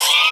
TS Chant 3.wav